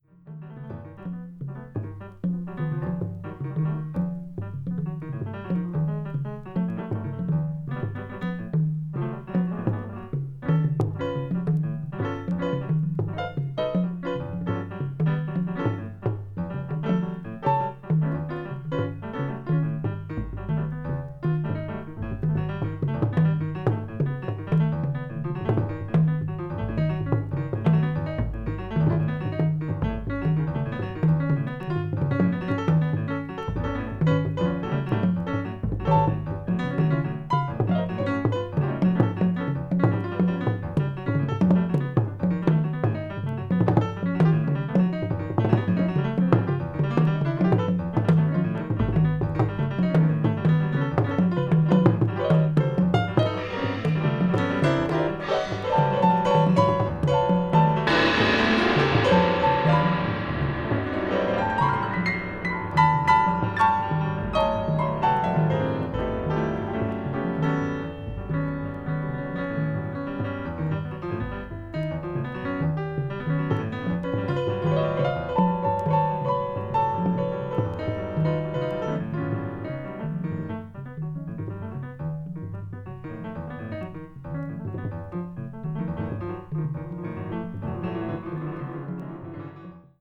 A1中盤に薄いスリキズが1本あり、軽いプチノイズが断続的に入りますので、ご了承ください。